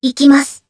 Kara-Vox_Attack2_jpb.wav